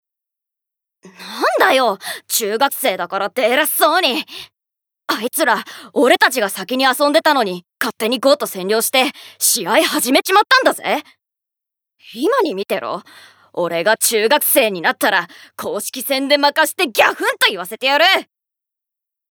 ボイスサンプル
セリフ４